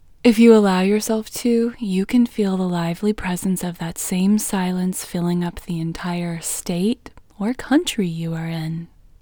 WHOLENESS English Female 10